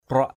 /krʊaʔ/ 1.
kruak.mp3